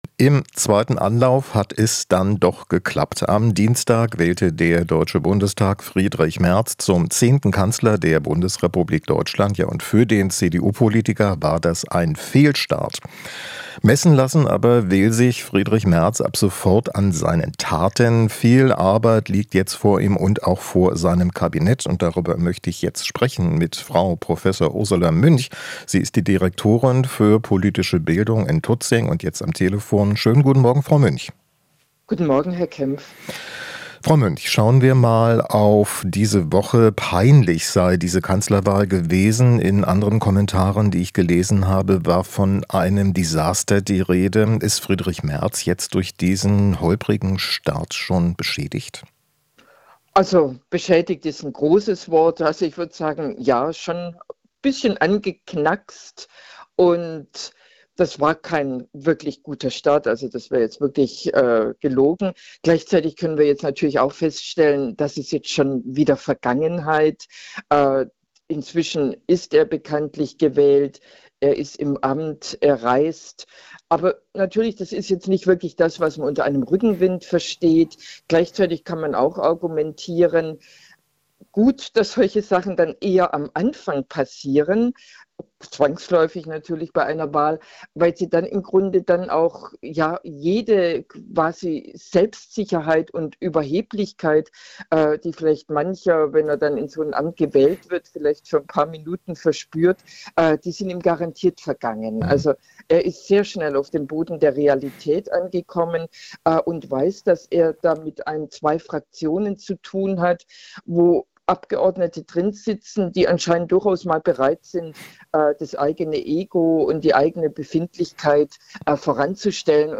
Interview - Politologin: Holpriger Start ist für Merz gute Mahnung